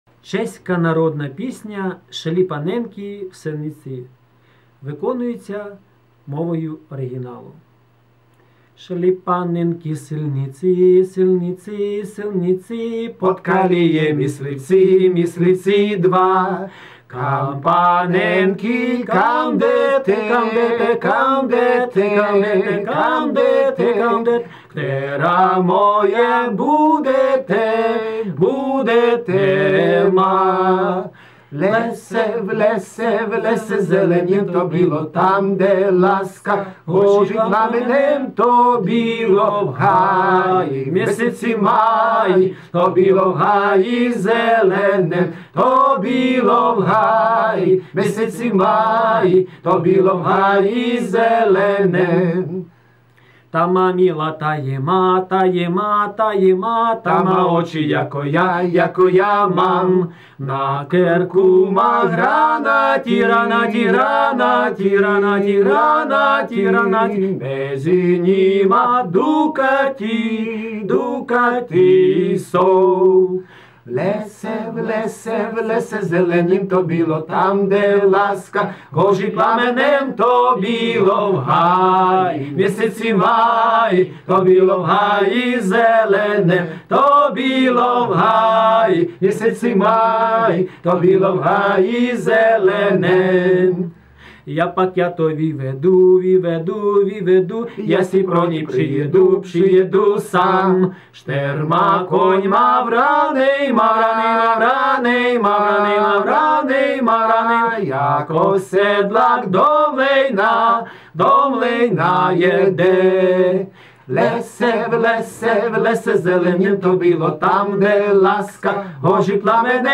Чешская полька восходит чуть ли не к XVII веку: